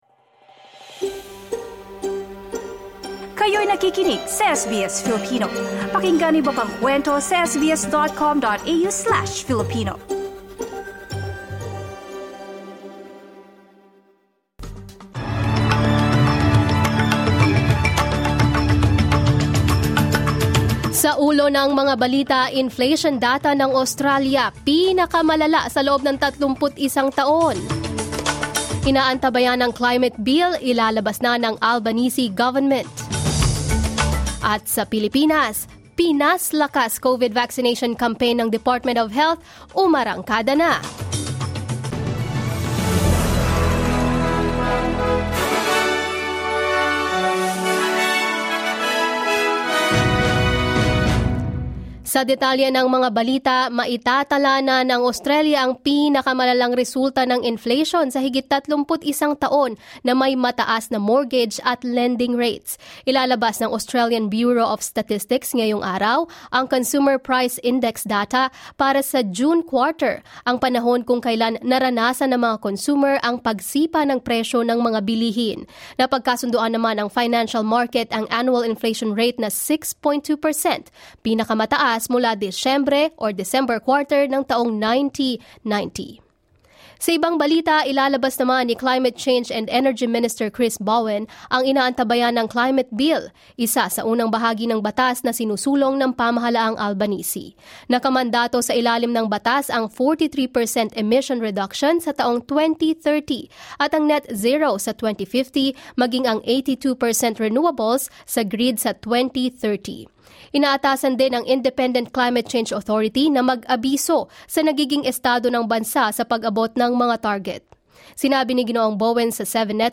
SBS News in Filipino, Wednesday 27 July